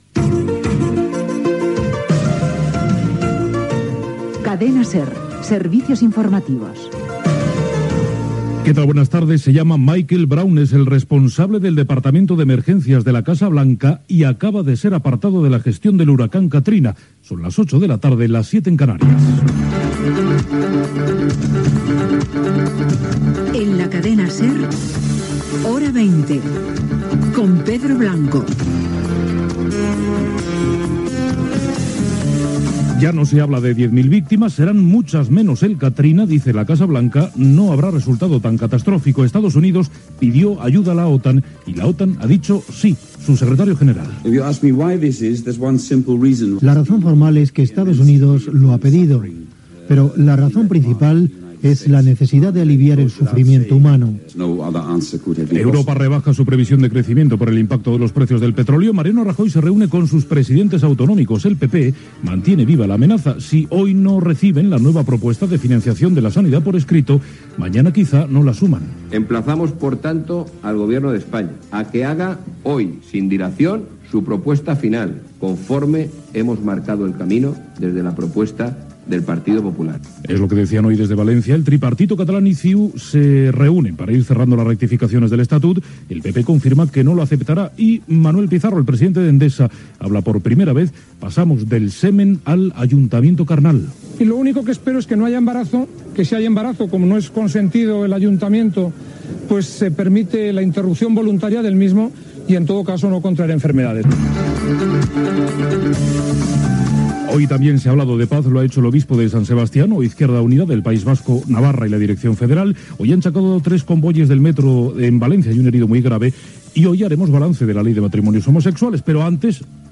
Indicatiu Serveis Informatius. Careta. Titulars informatius: huracà Kathrina, reunió de presidents autonòmics del PP, etc. El temps.
Informatiu